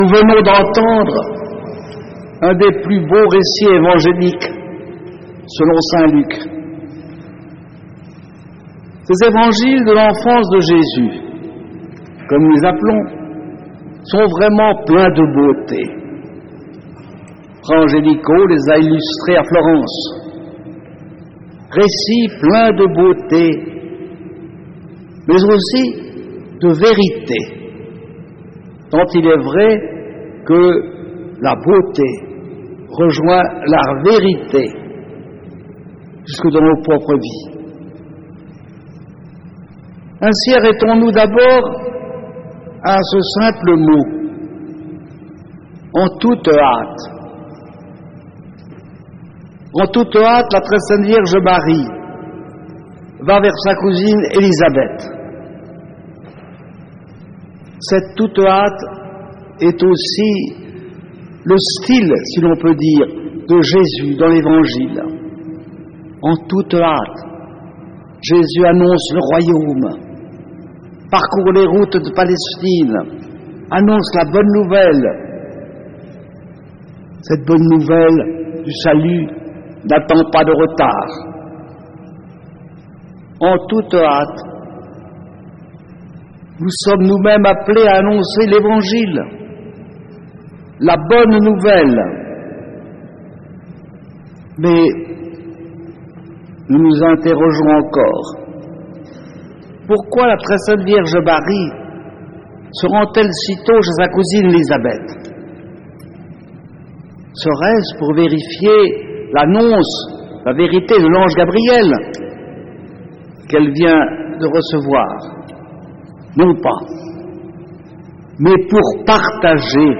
Catégories homélies